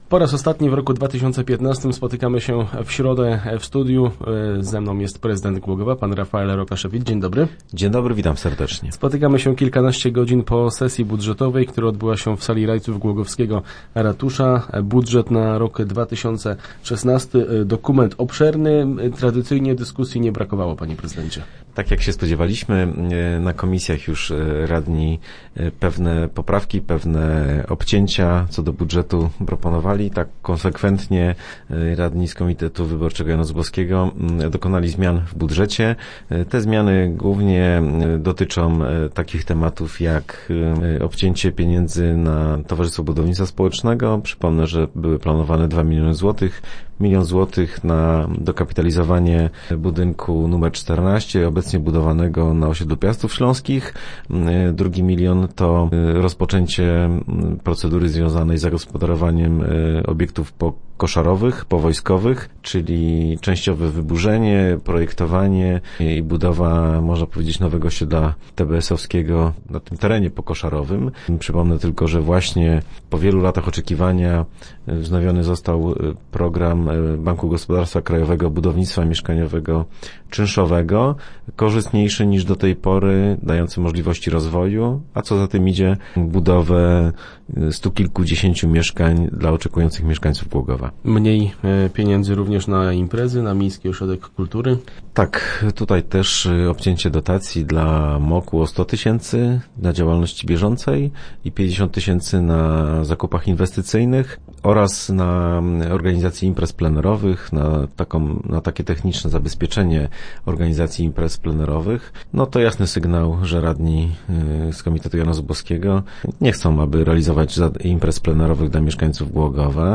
0909_re_rokasz.jpgRadni miejscy przyjęli budżet Głogowa na 2016 rok. Ostatnią w roku bieżącym sesje podsumował w radiowym studiu  prezydent miasta Rafael Rokaszewicz.